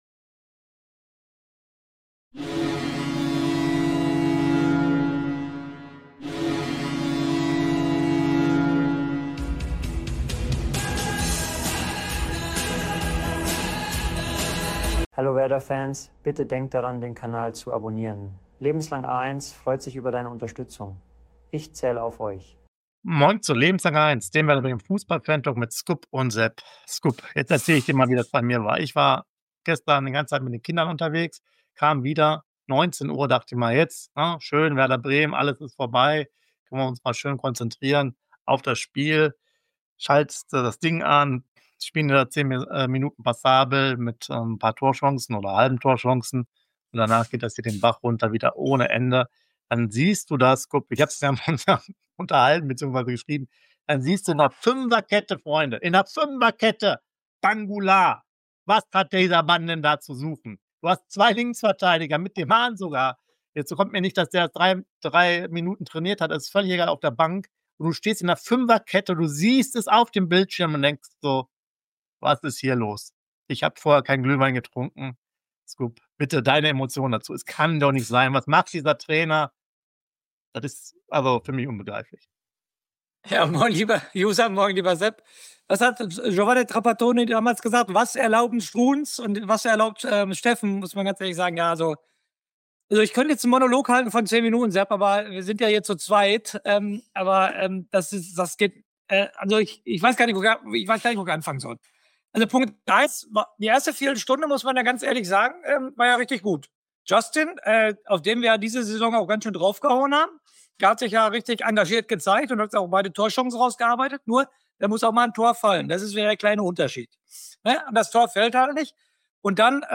Fantalk